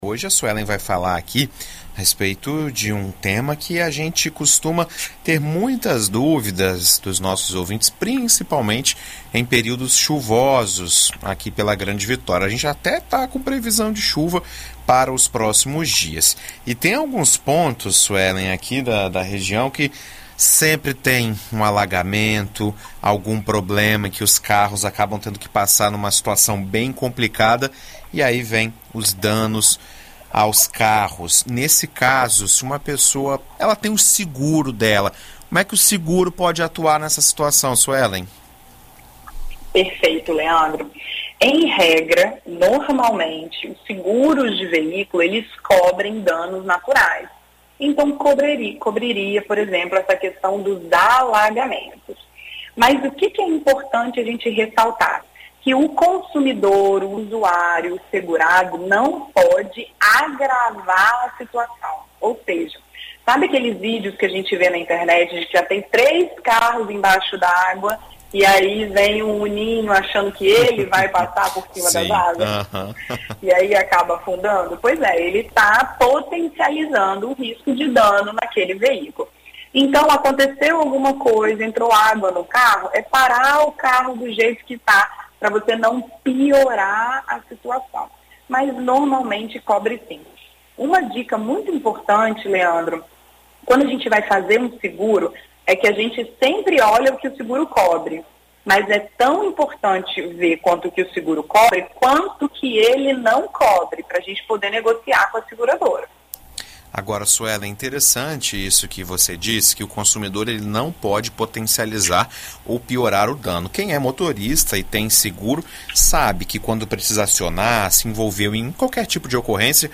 Em entrevista à BandNews FM